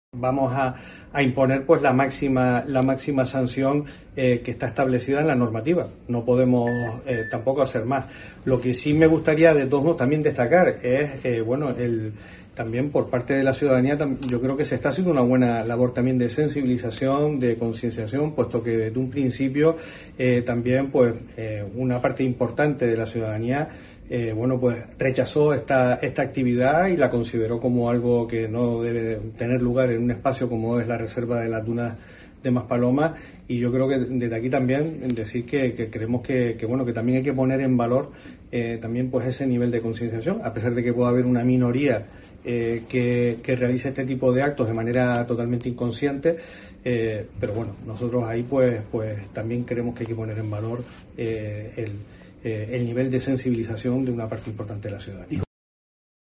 Raúl García Brink, consejero de Medioambiente del Cabildo de Gran Canaria